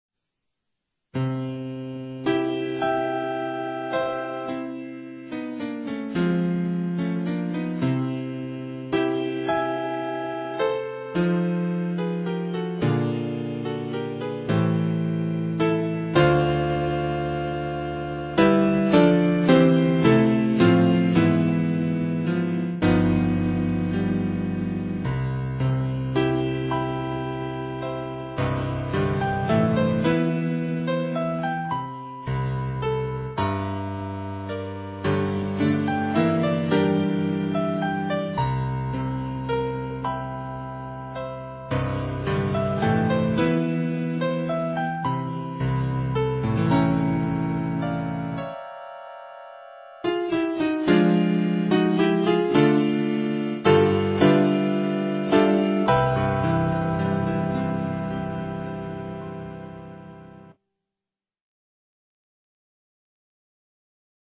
for piano, in C major